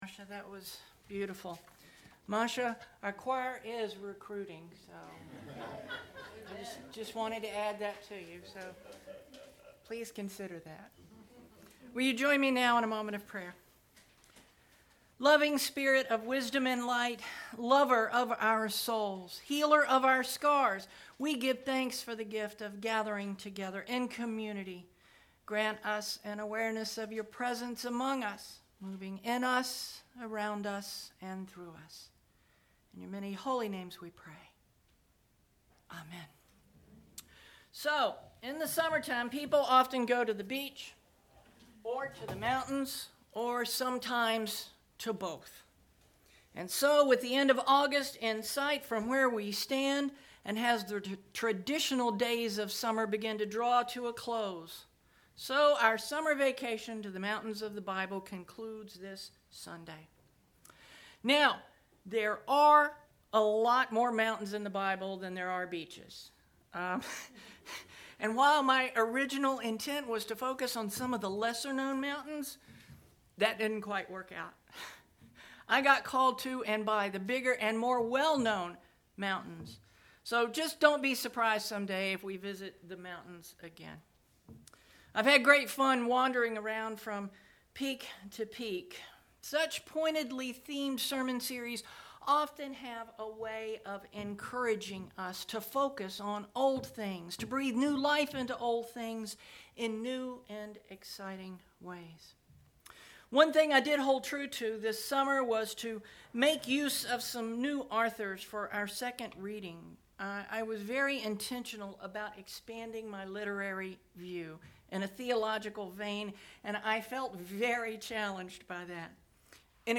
8/27 Sermon Posted